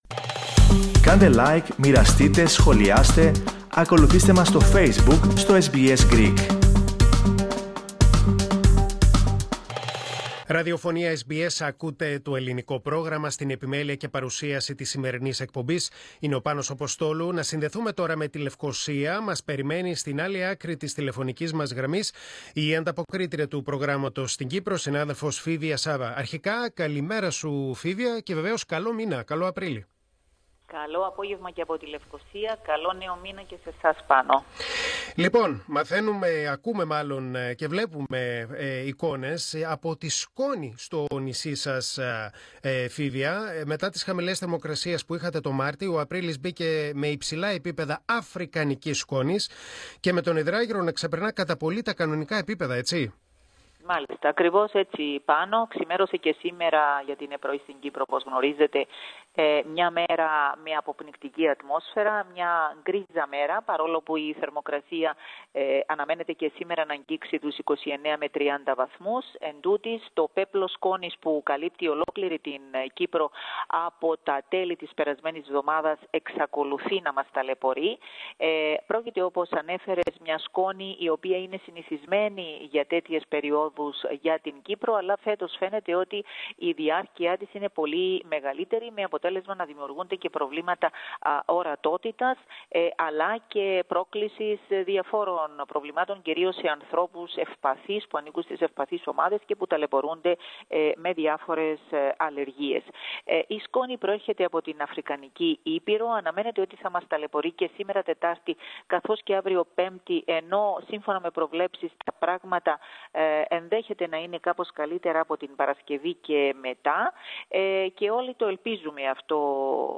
Ακούστε ολόκληρη την ανταπόκριση από την Κύπρο, πατώντας το σύμβολο στο μέσο της κεντρικής φωτογραφίας.